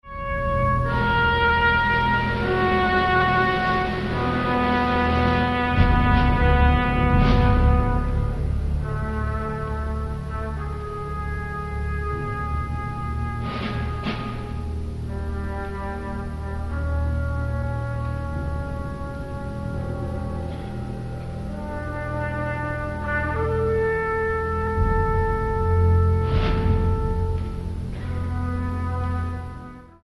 3-2-2-2, 4-2-3-1, Timp., Perc. (2), Str.